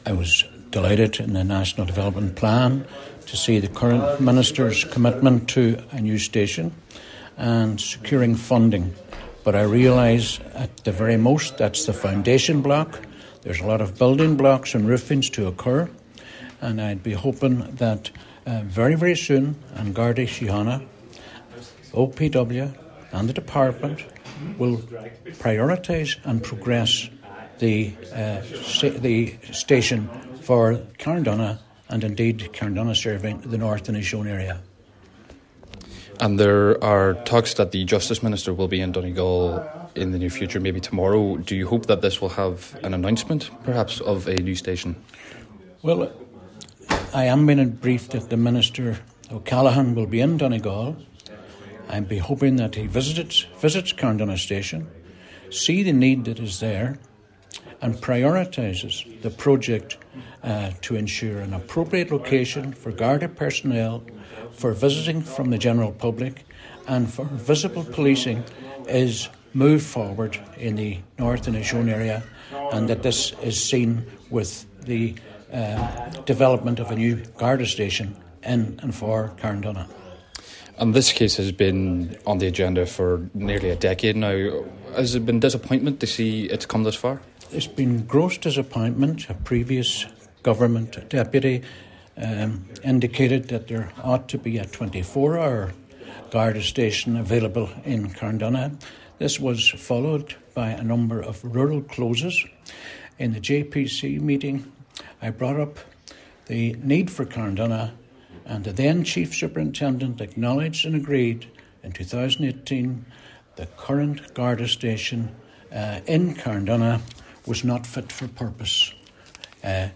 Cllr Albert Doherty says he hopes a new building will be prioritised to service North Inishowen, and he intends to ask Justice Minister Jim O’Callaghan about the proposal during the Minister’s visit to the county today: